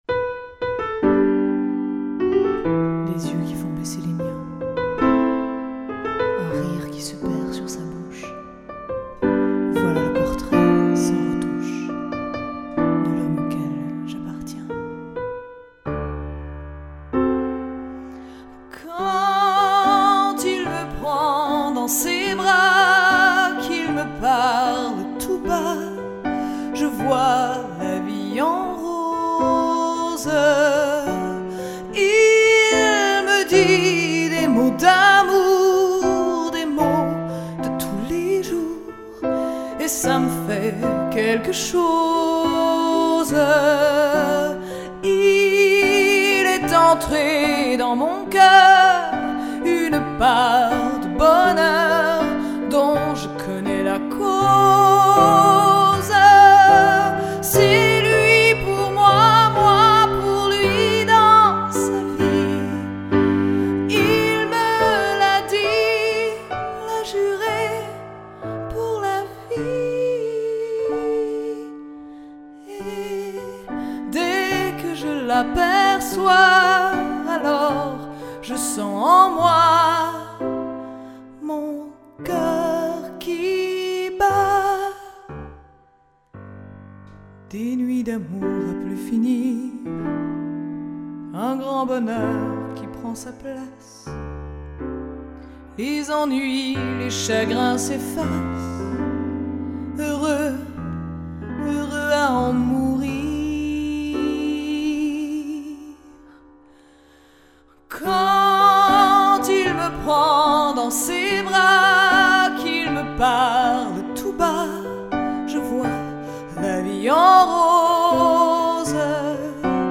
Chanteuse, animatrice, DJ
Piano Bar
piano/voix